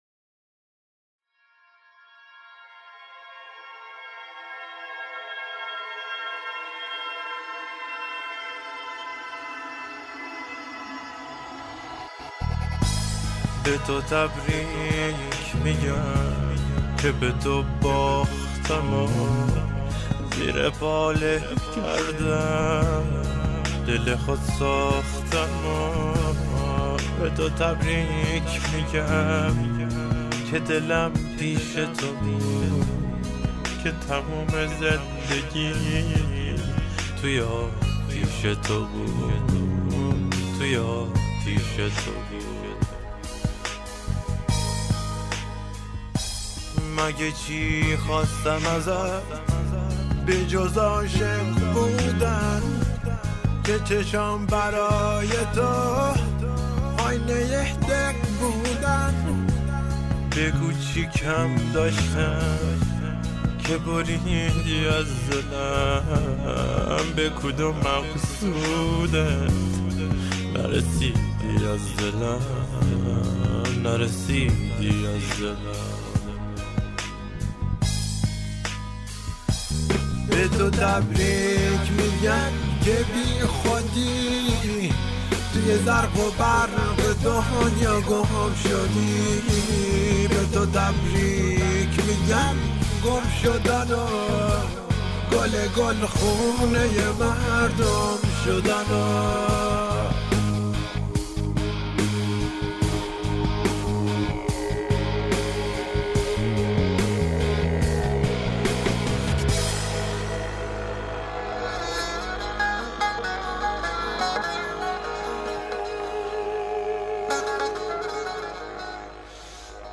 ポップアーティスト